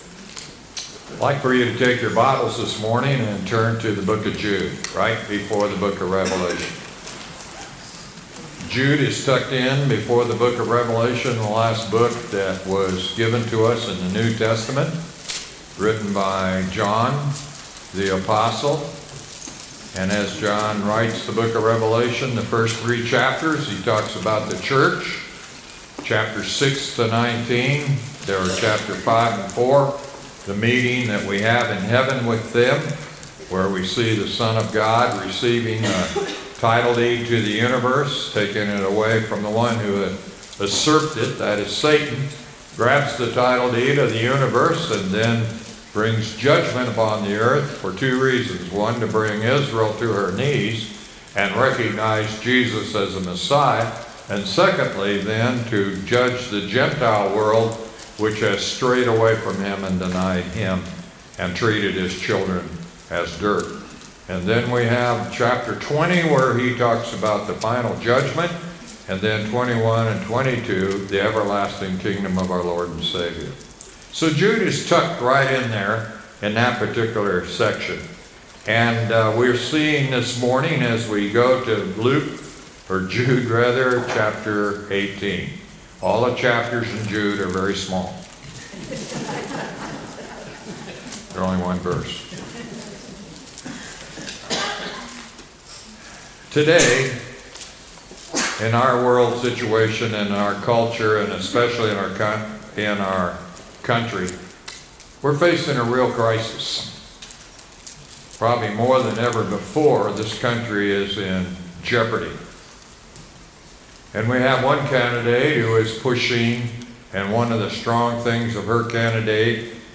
sermon-9-22-24.mp3